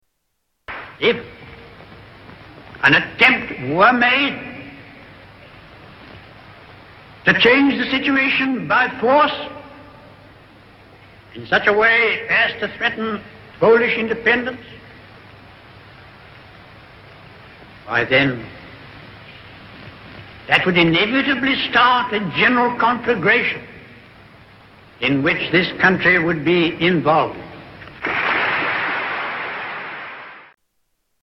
Tags: Historical Neville Chamberlain Audio Neville Chamberlain Speeches Arthur Neville Chamberlain Neville Chamberlain Sounds